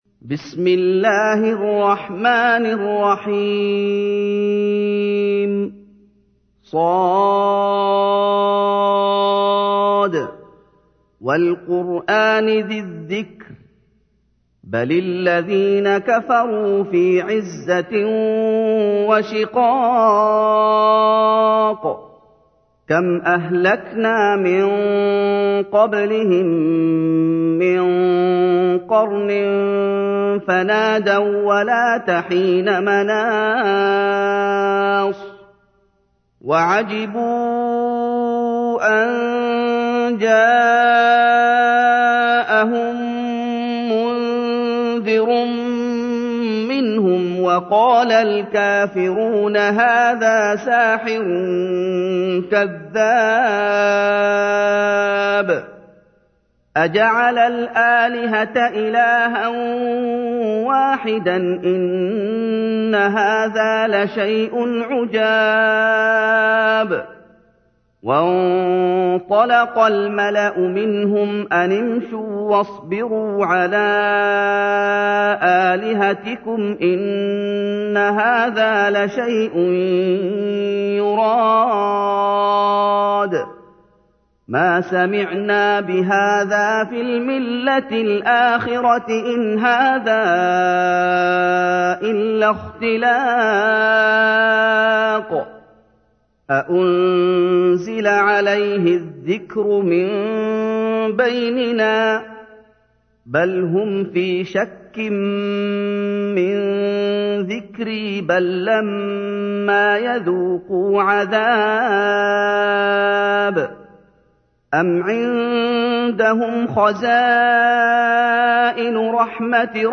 تحميل : 38. سورة ص / القارئ محمد أيوب / القرآن الكريم / موقع يا حسين